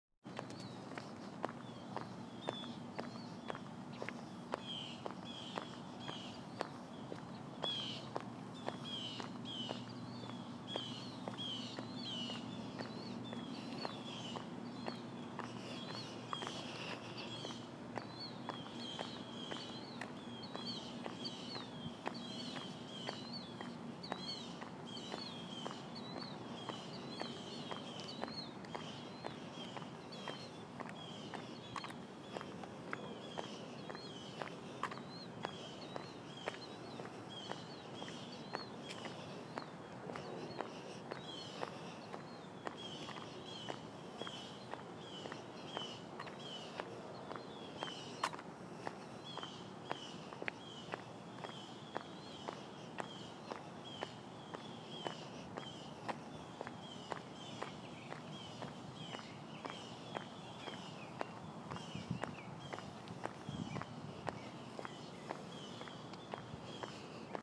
Birdsong, brisk steps and a few gasps of human breathing.
morning-walk.m4a